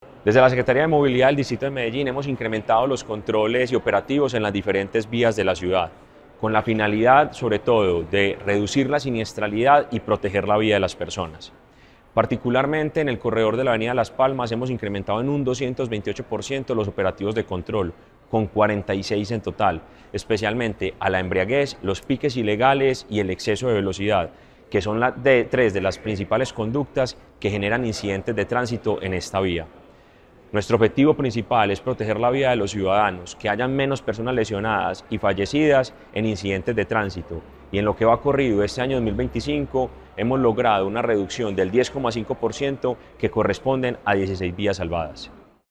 Declaraciones-del-secretario-de-Movilidad-Mateo-Gonzalez-Piques-ilegales.mp3